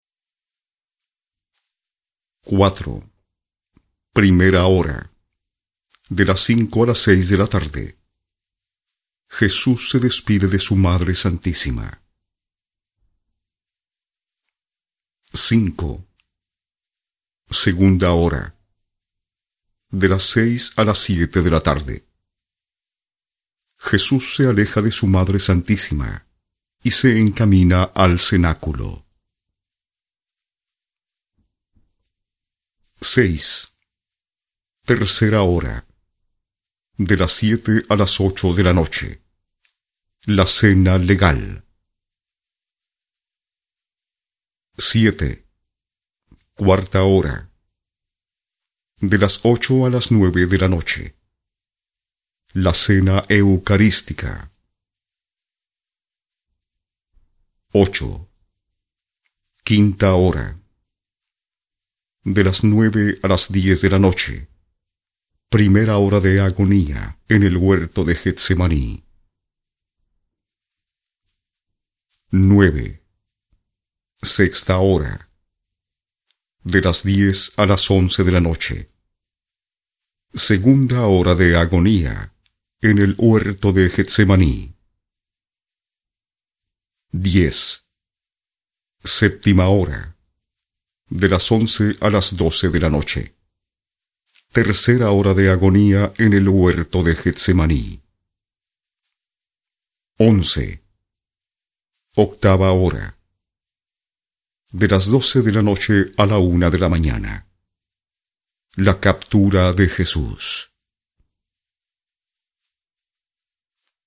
Spanish (neutral) speaker.
Sprechprobe: Industrie (Muttersprache):
My voice is warm, institutional